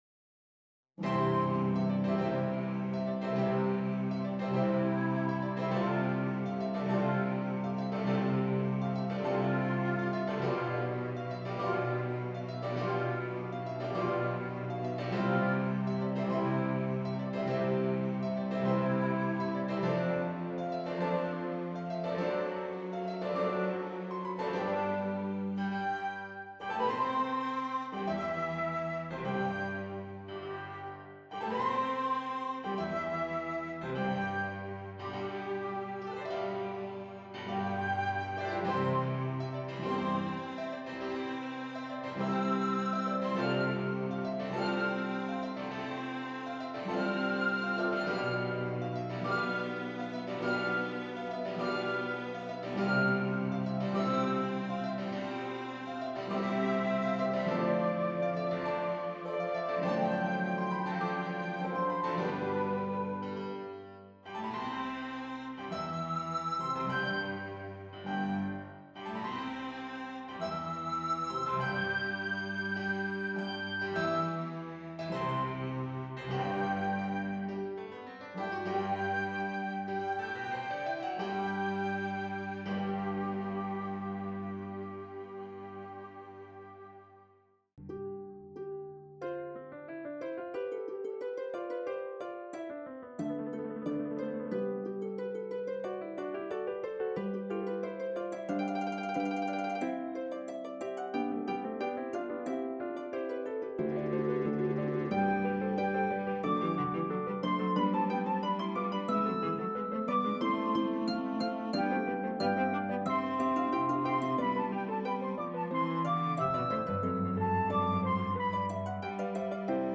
Hallelujah-Chorus-arranged-as-duetts-for-Harp-Flute-cello-and-piano-1.mp3